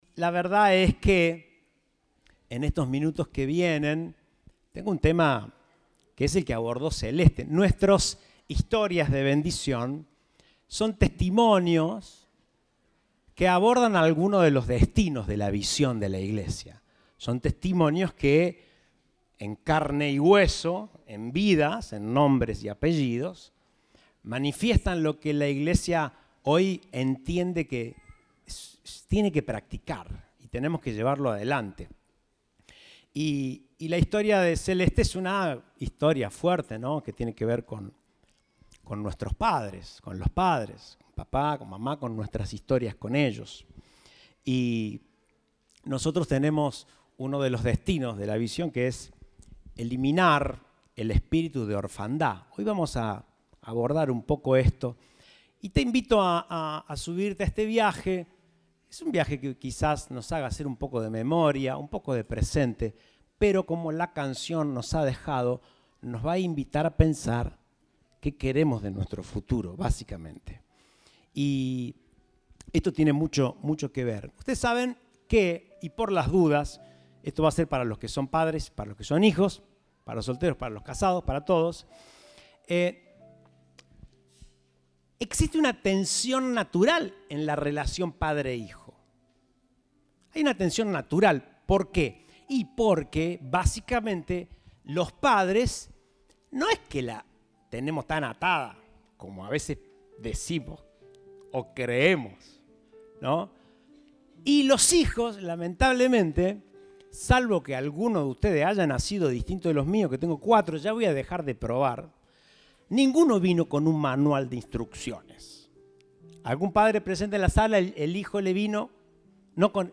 Compartimos el mensaje del Domingo 11 de Junio de 2023